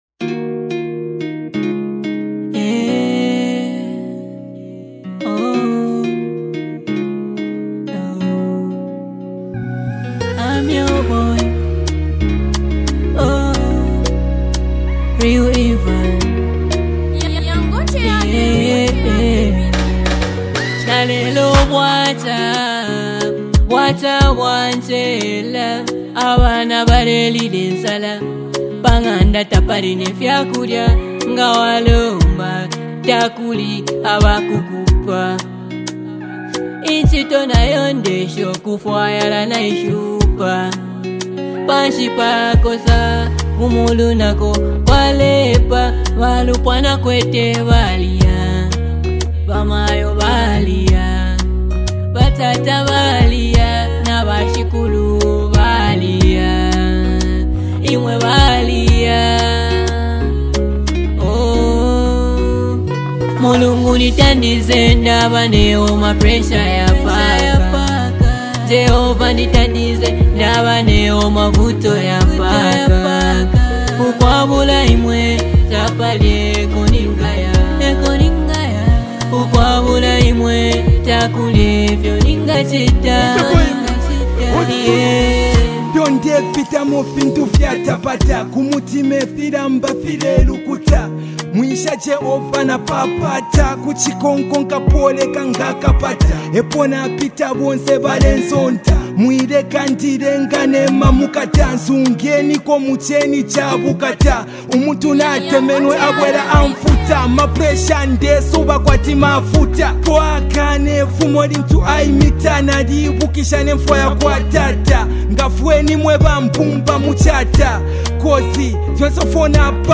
Gospel Songs